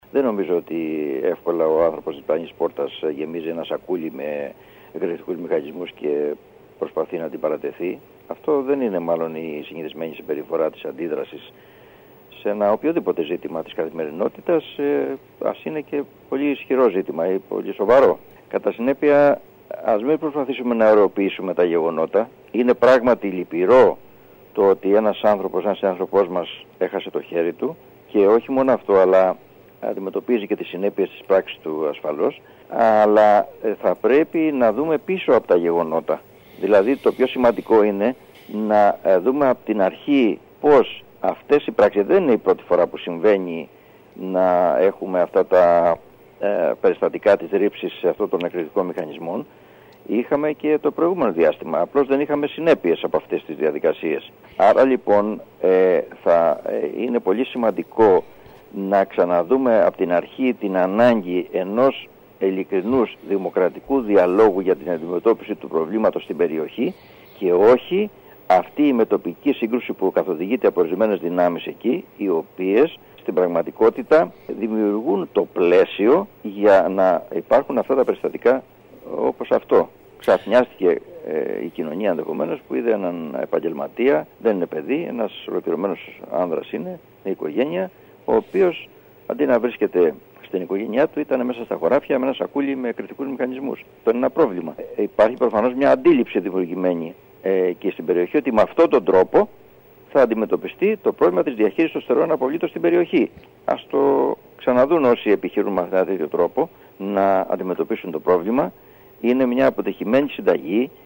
Είναι λυπηρό ότι ένας συνάνθρωπός μας έχασε το χέρι του αλλά ας μην προσπαθούμε να ωραιοποιήσουμε τα γεγονότα, δηλώνει ο Περιφερειάρχης Ιονίων Νήσων Θόδωρος Γαλιατσάτος μιλώντας σήμερα στην ΕΡΤ Κέρκυρας.